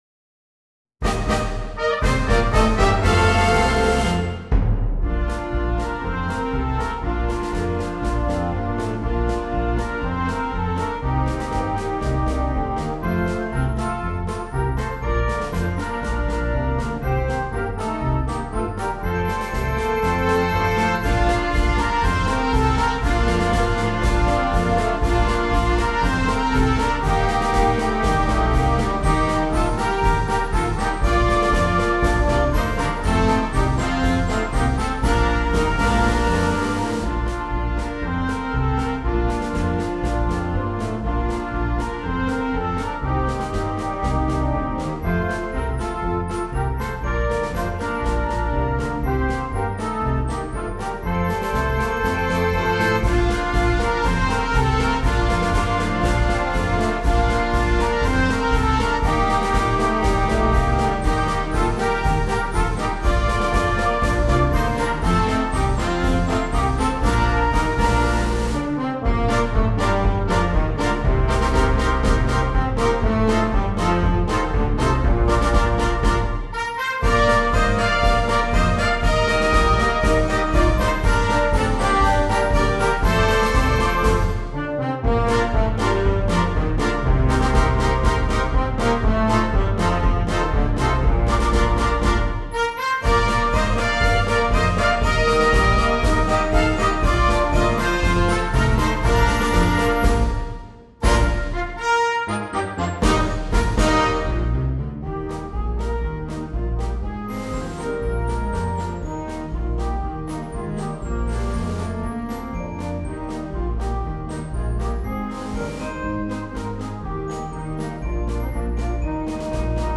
Marcia per Banda
(Marcia brillante).
Organico: Casual Band
Ottavino
Flauto 1-2
Clarinetto in Sib 1-2
Sax Alto 1-2
Tromba in Sib 1-2
Glockenspiel
Cassa e Piatti